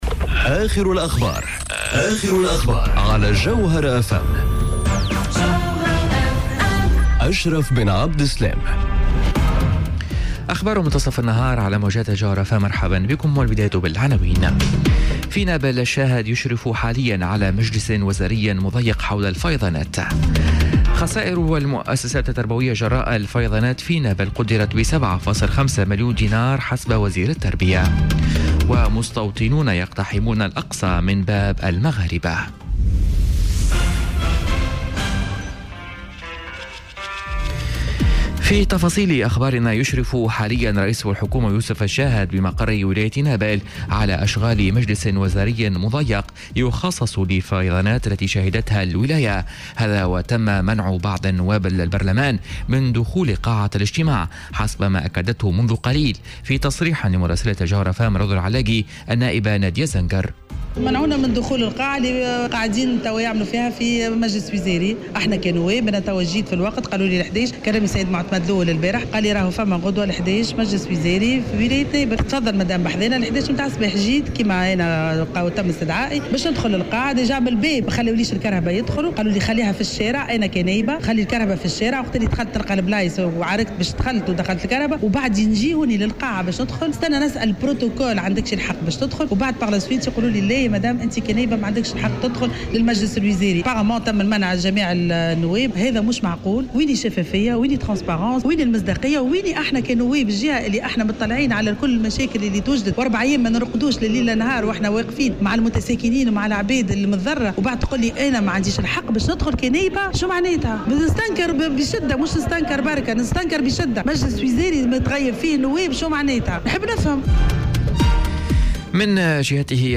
نشرة أخبار منتصف النهار ليوم الإربعاء 26 سبتمبر 2018